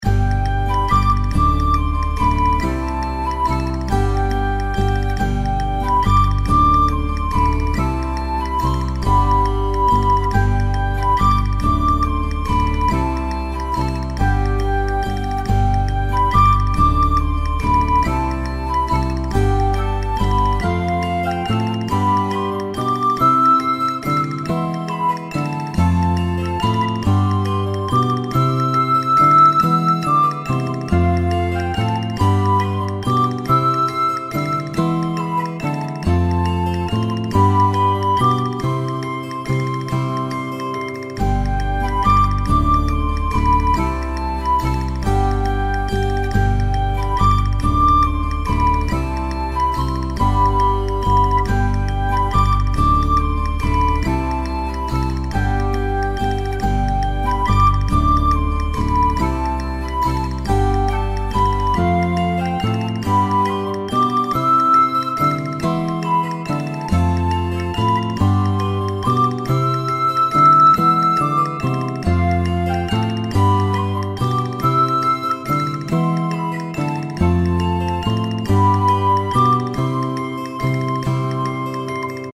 • テンポはおだやか（約78BPM）で、心の揺れを静かに表現
• アコースティックギターを中心に構成し、素朴で温かみのあるサウンド
• フルートの旋律が“想い出の風景”を柔らかく彩る
• 軽めのパーカッションをうっすらと敷いて、静けさの中にも生命感を残している
• リバーブ処理は控えめにし、空間より“近さ”を重視して仕上げています